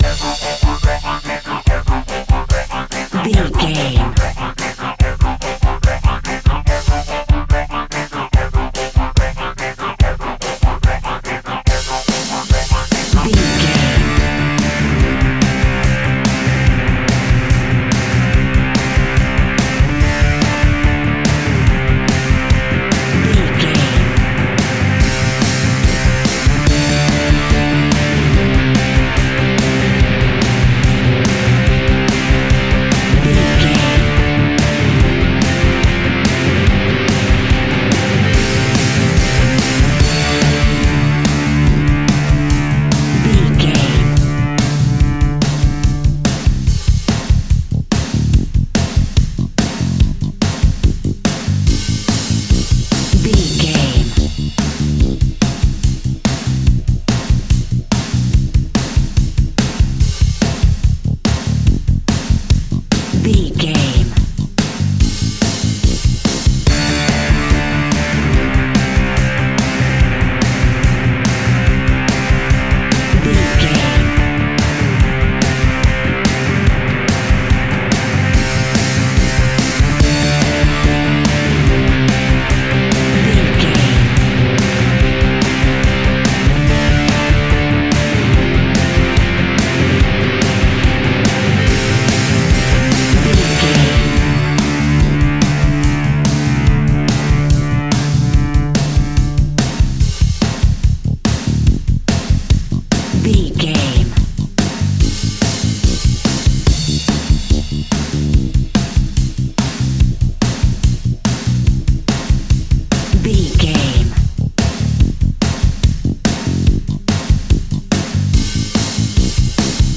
Epic / Action
Fast paced
Mixolydian
hard rock
blues rock
distortion
rock instrumentals
Rock Bass
Rock Drums
distorted guitars
hammond organ